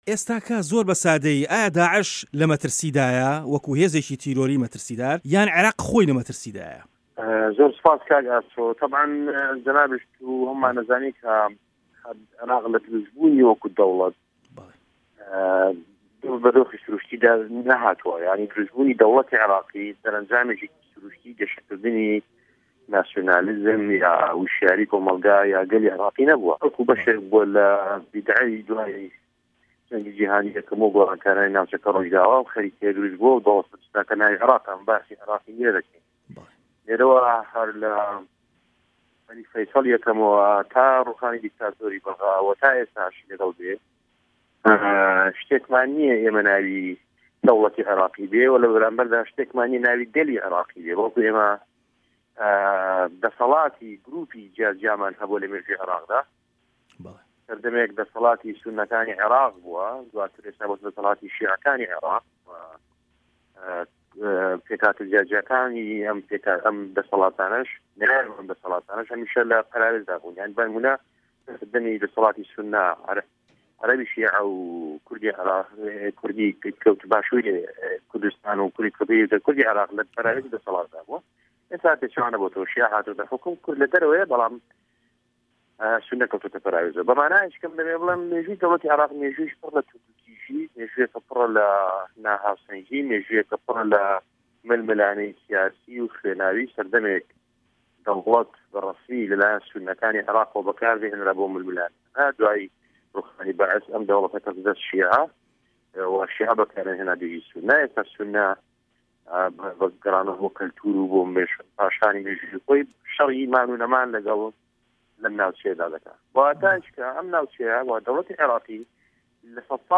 وتووێژە